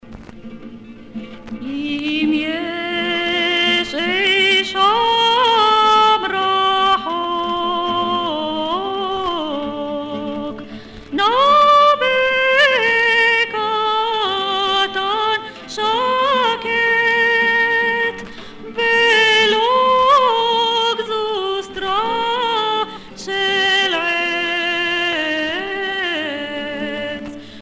Chants et danses des pionniers
Pièce musicale éditée